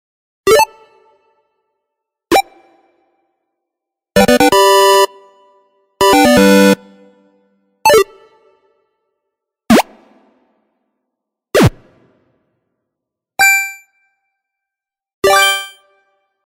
Восьмибитные звуки из ретро видеоигр для монтажа видео и просто вспомнить детство.
5. Сборник звуков 8 бит #4